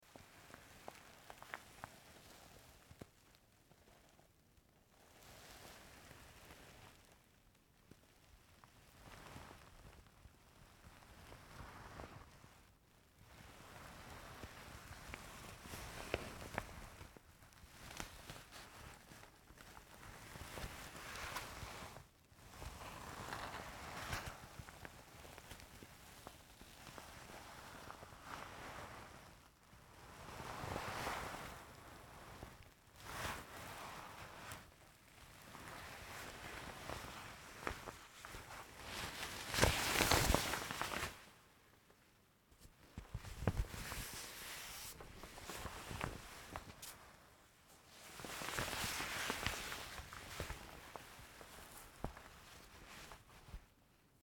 Звук протирания скатерти и её выравнивание по столу